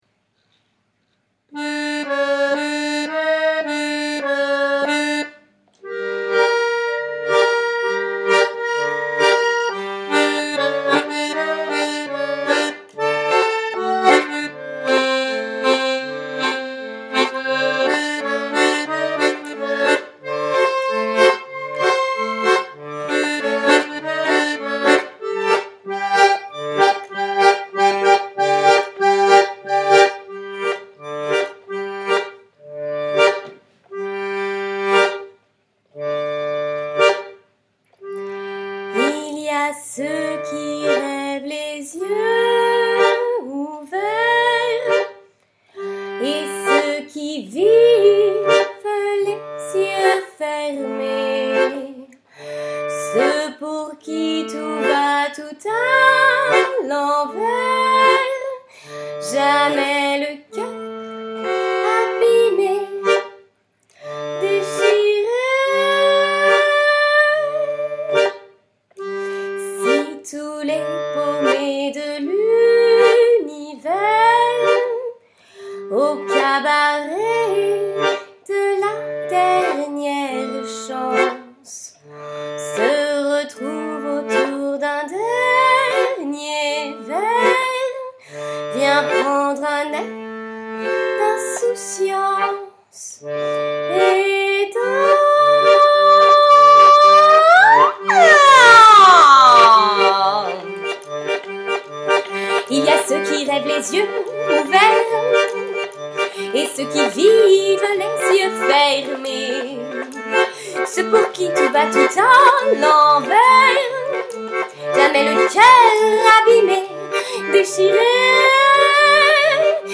Le cabaret de la dernière chance - Accordéon + Voix